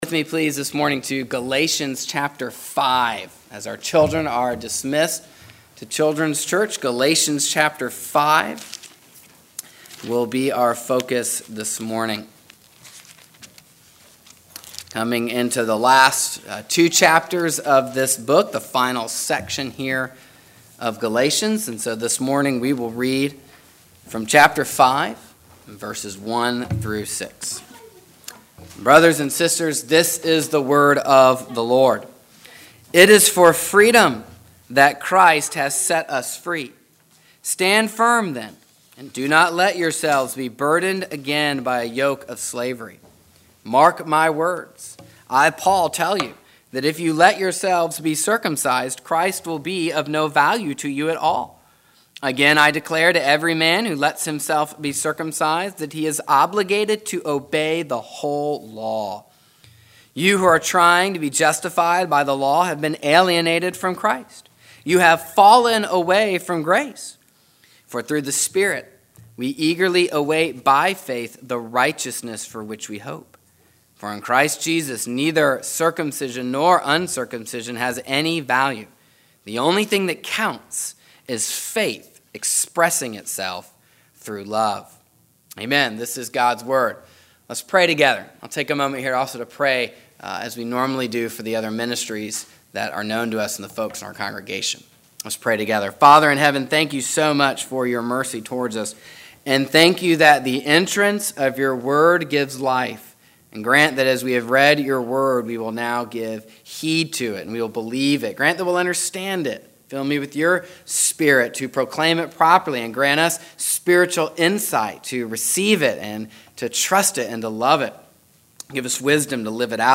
This sermon concerns charting a course between two evils. There are two deadly errors that can kill the gospel. The first error is trying to earn your justification by works. The second is using your freedom in Christ to engage in a sinful lifestyle.